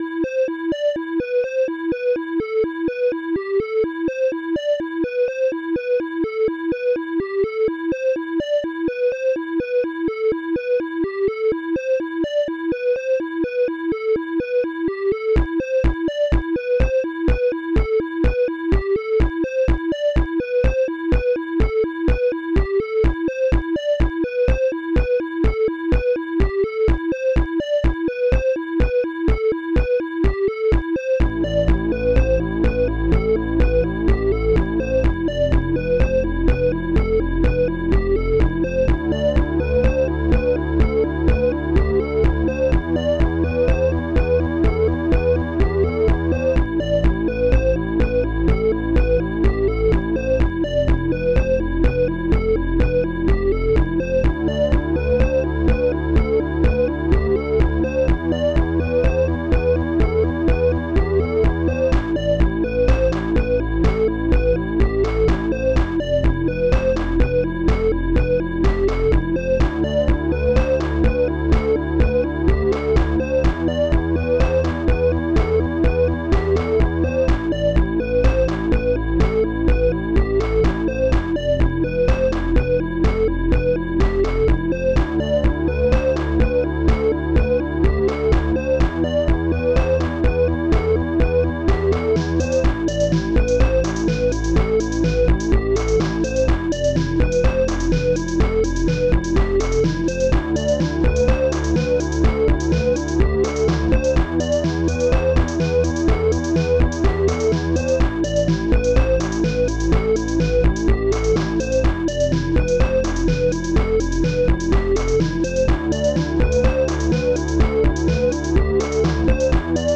sinecz bassdrum1 analogstring claps1 snare3 hihat2 strings3 electom woodblock popbass strings2